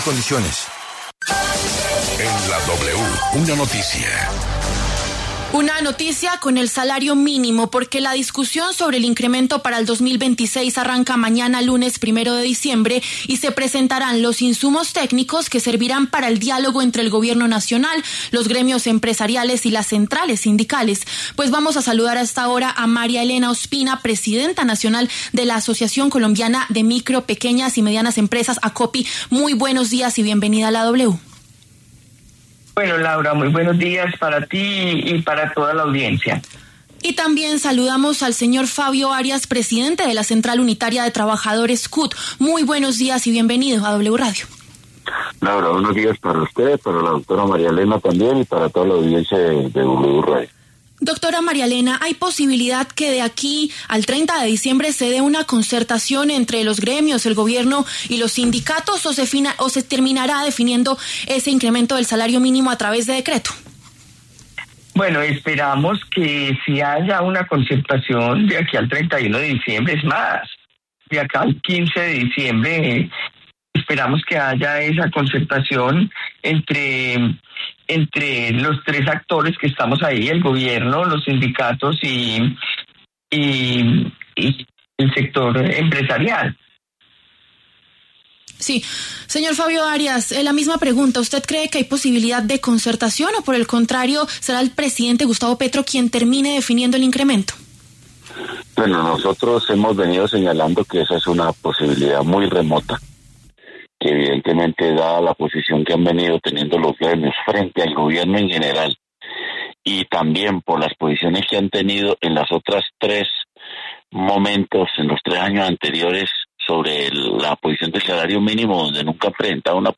¿Se podrá concertar el salario mínimo para 2026 o irá por decreto? Empresas y sindicatos debaten